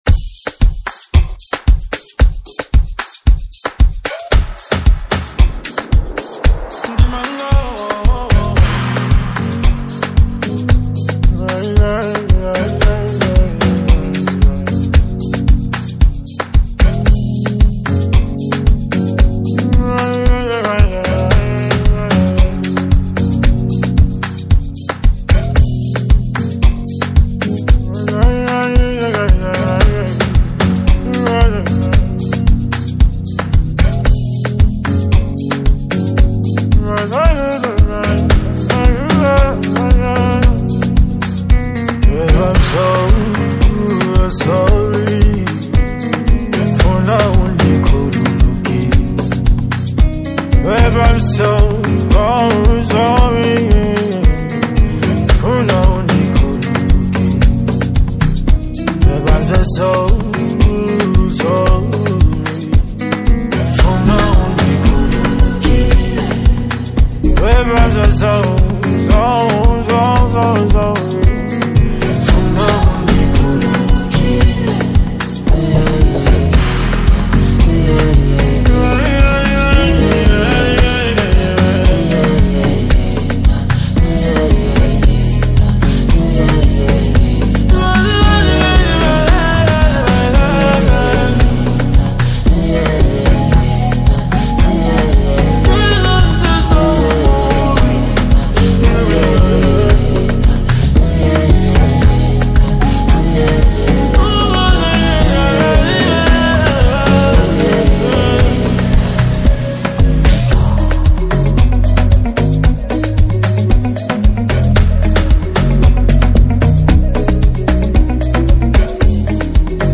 combines emotional lyrics with a touching melody